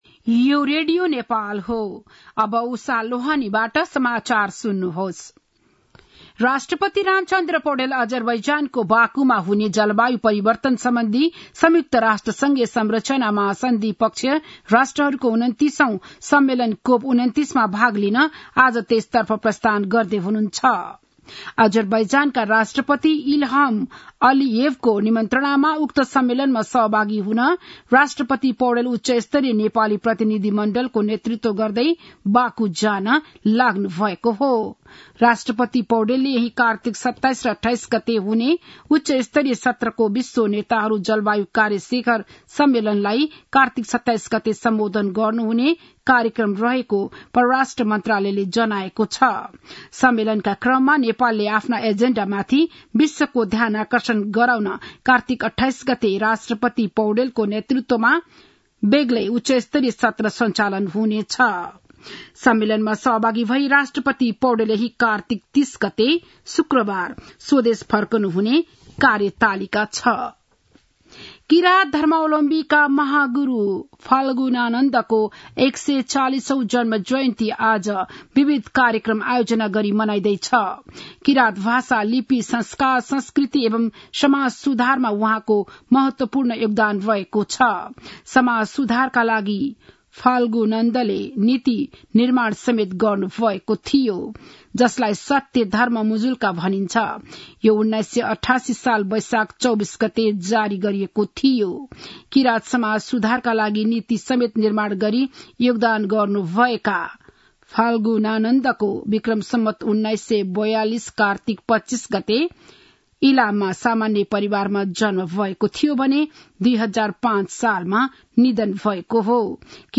बिहान ११ बजेको नेपाली समाचार : २६ कार्तिक , २०८१
11-am-news-2.mp3